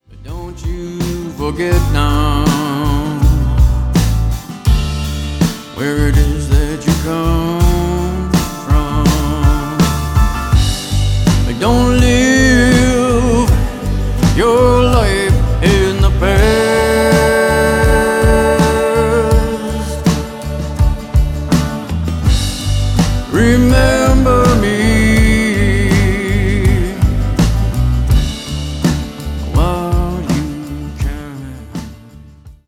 Canadian Singer-Songwriter
GENRE : Americana